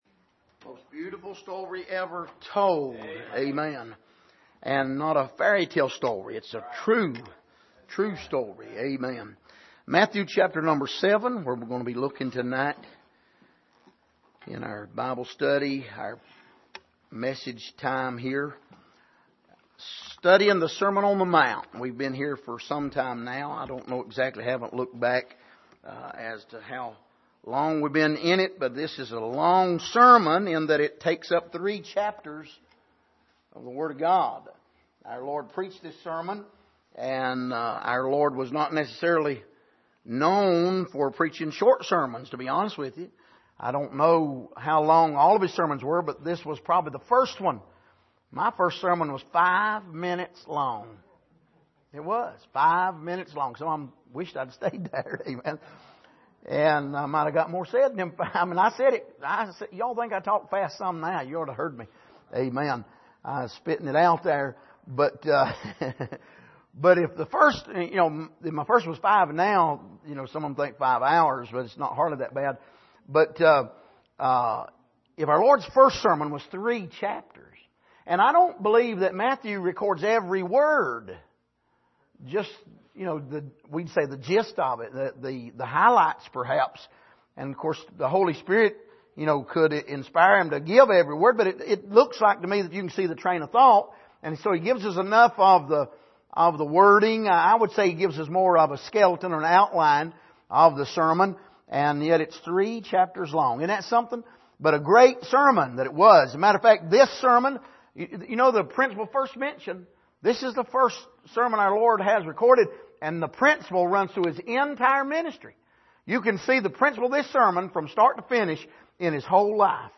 Passage: Matthew 7:7-11 Service: Sunday Evening